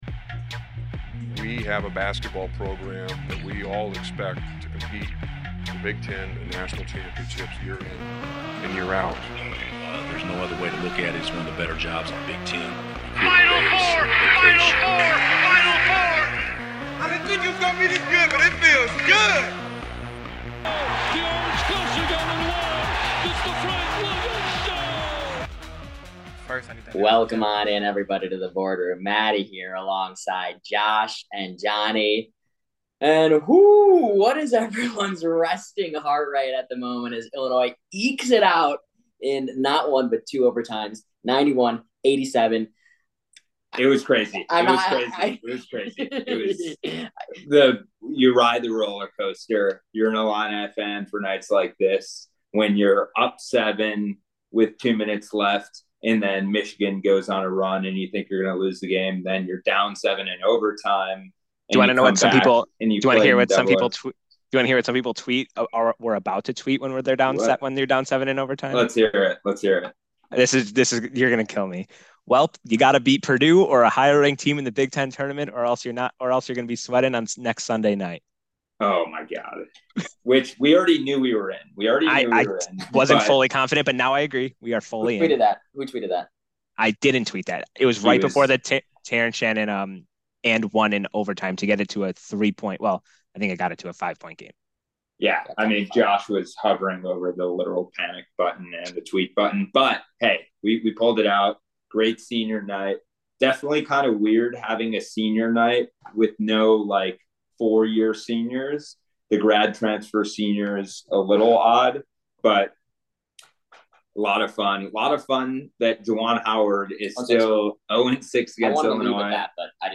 The game was so exciting that even our recording device gave out midway. Not to worry, the guys still came to play and talk about the 2OT win, and rub some salt into the wound on Michigan and Mr. Dickinson.